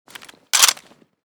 sks_open.ogg.bak